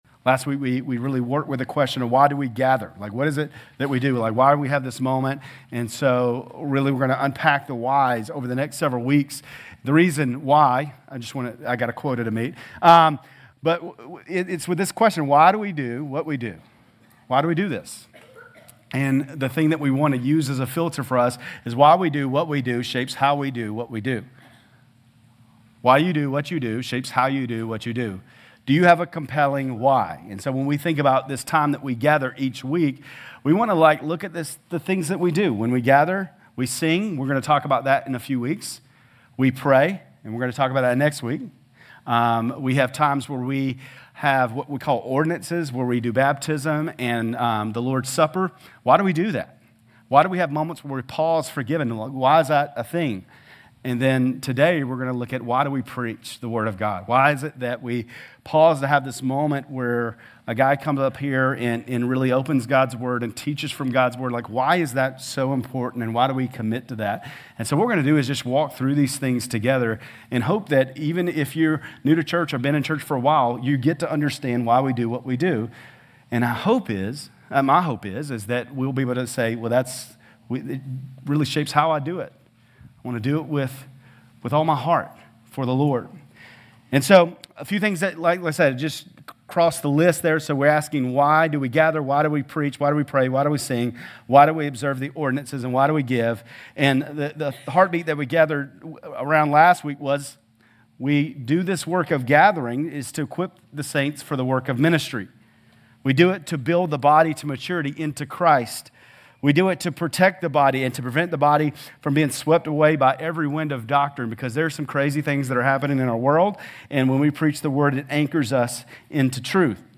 GCC-Lindale-September-3-Sermon.mp3